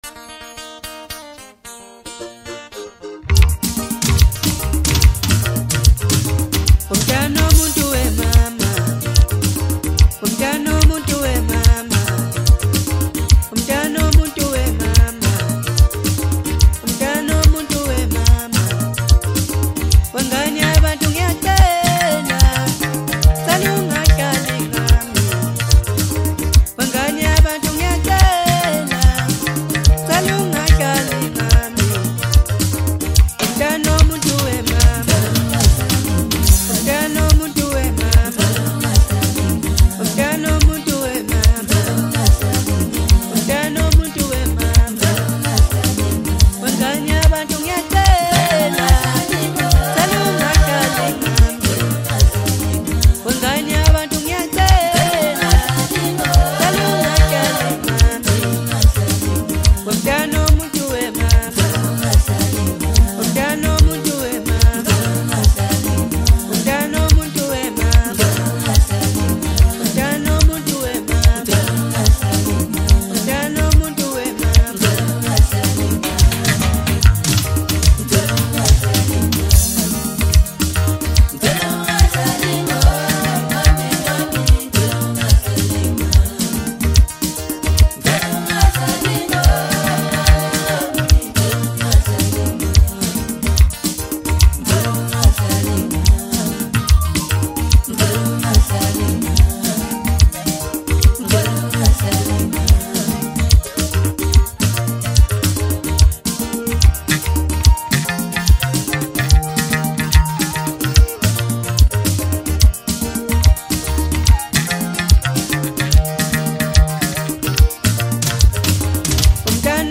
Home » Maskandi » DJ Mix
South African singer-songwriter